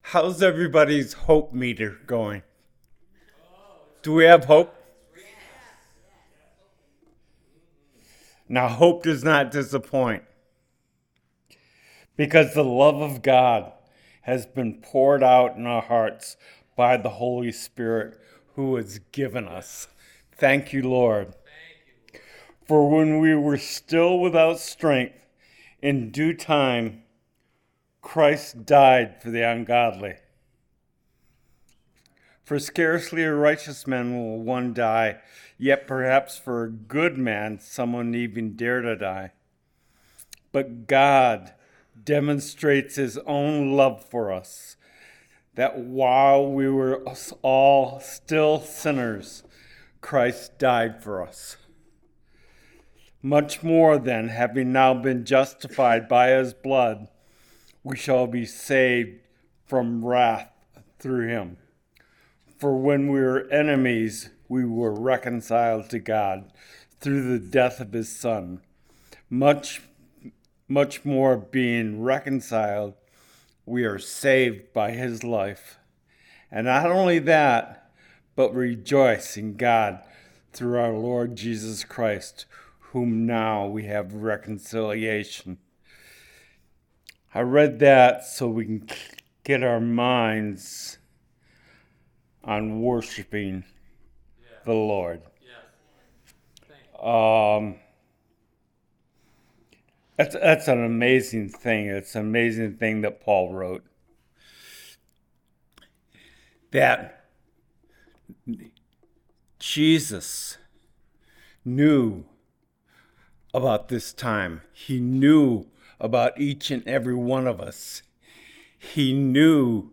Palm Sunday Service: March 29, 2026 Communion Message
Service Type: Sunday Service